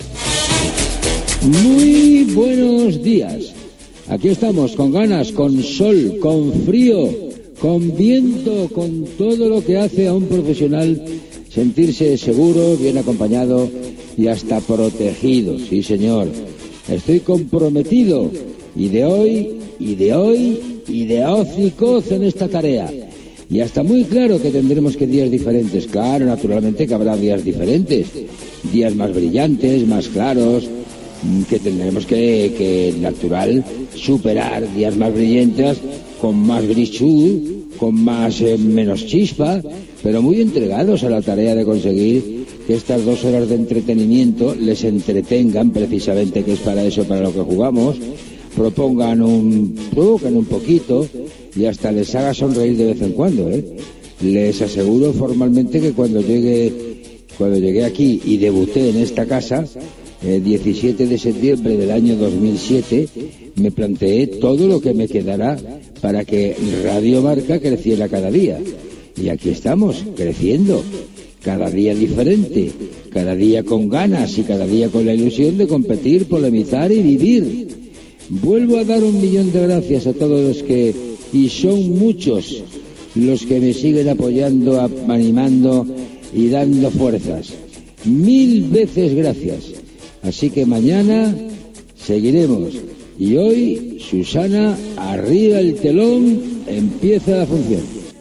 Inici i comiat del programa.
Esportiu
FM